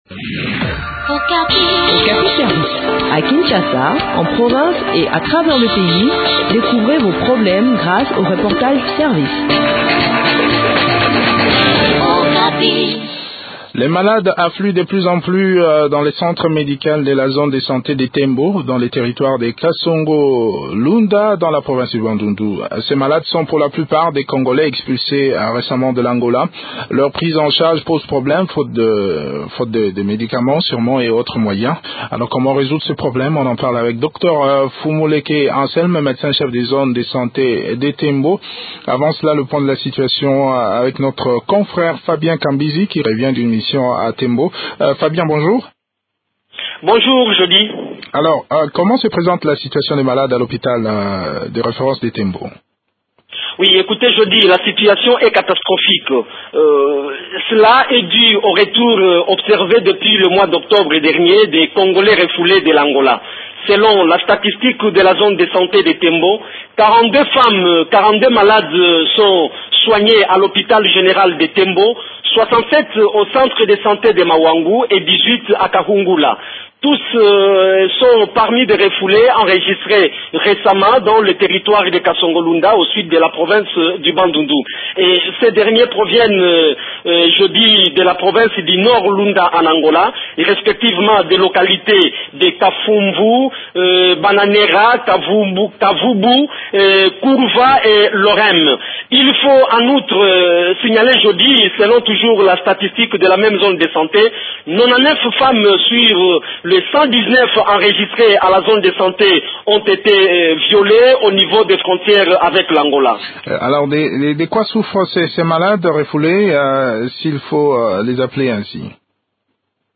Le point de la situation sur le terrain dans cet entretien